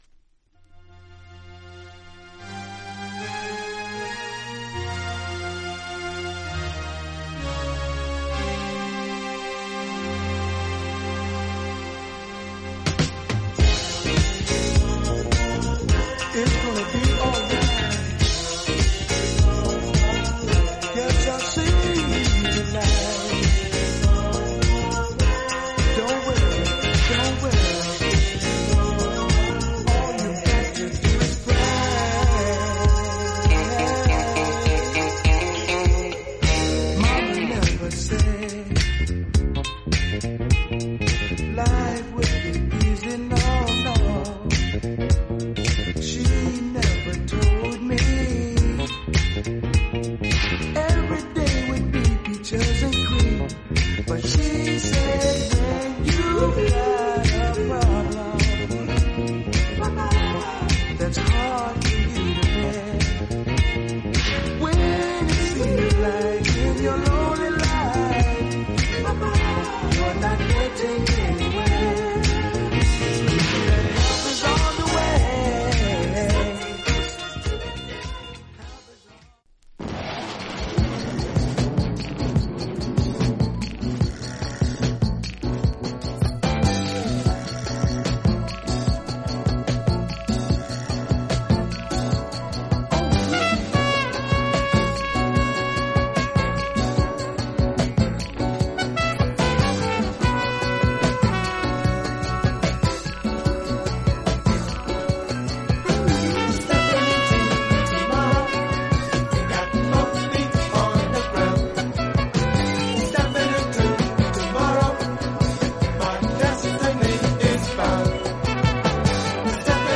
R&B、ソウル
実際のレコードからのサンプル↓